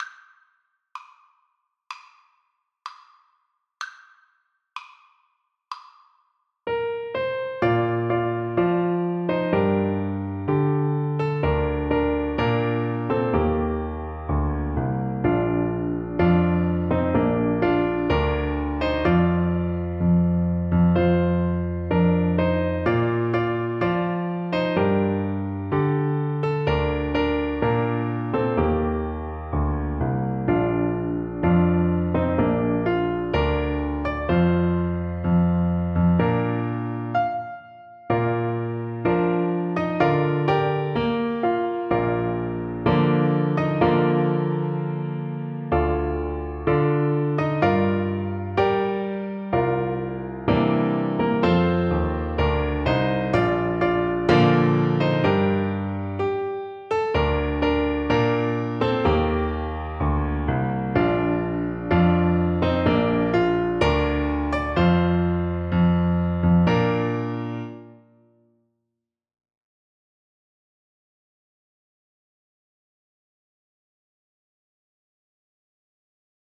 4/4 (View more 4/4 Music)
Moderato
Traditional (View more Traditional Voice Music)